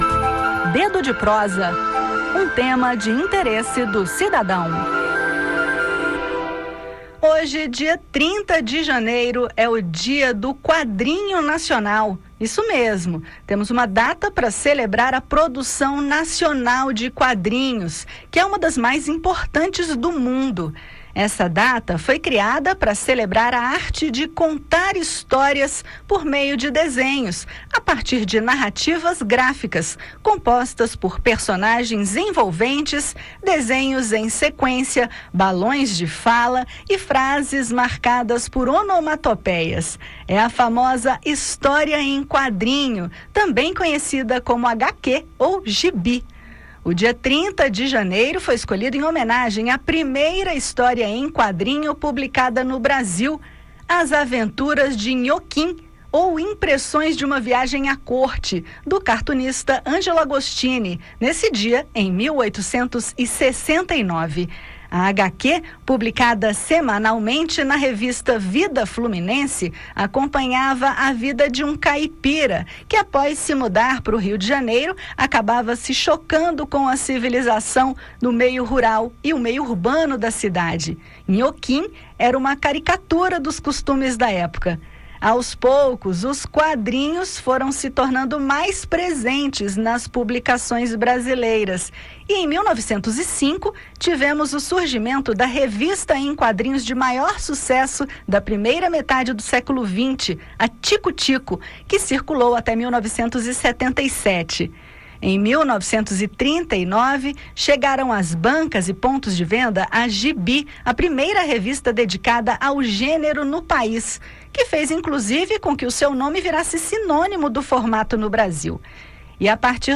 No bate-papo, ouça detalhes sobre a publicação, a importância das HQs na formação do leitor e dicas de outros quadrinhos brasileiros de relevância.